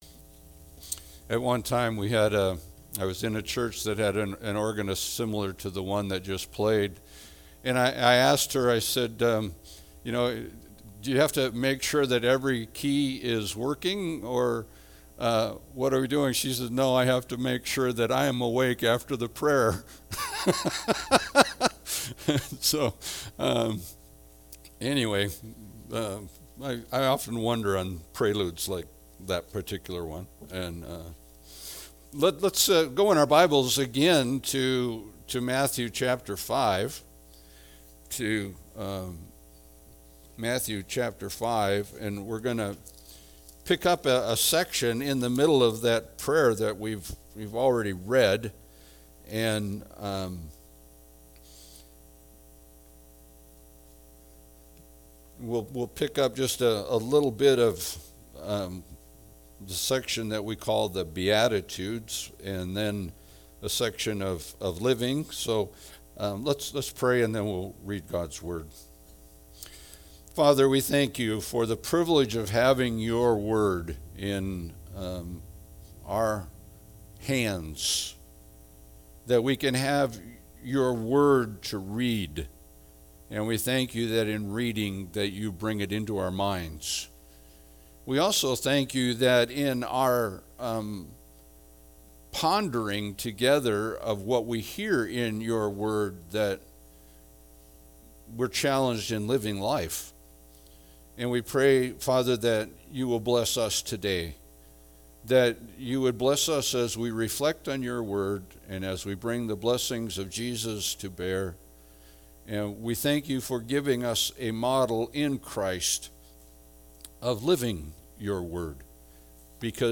Passage: Matthew 5:11-20 Service Type: Sunday Service